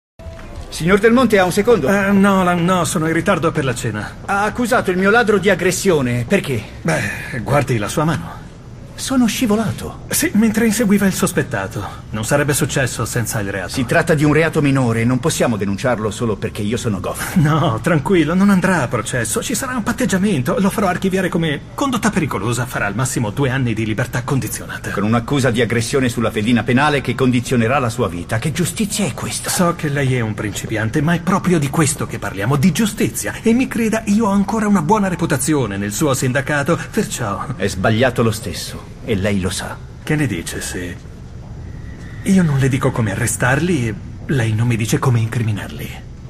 The Rookie", in cui doppia Michael Trucco.